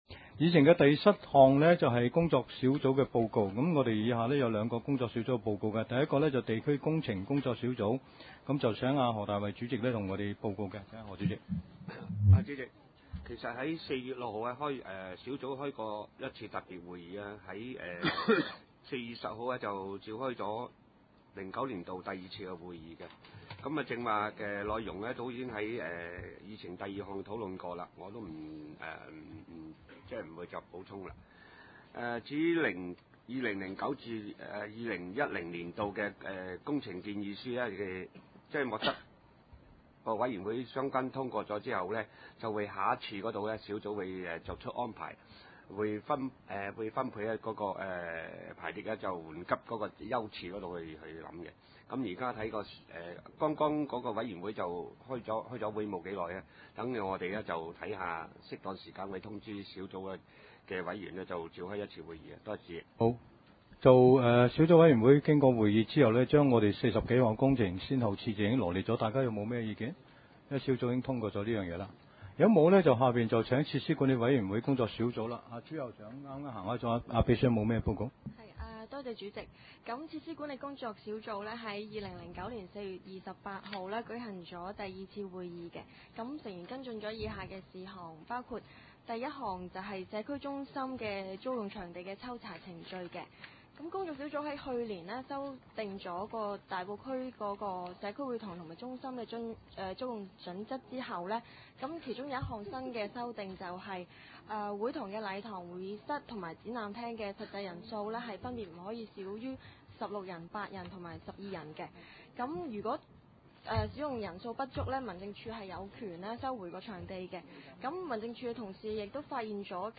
地區設施管理委員會2009年第三次會議
地點：大埔區議會秘書處會議室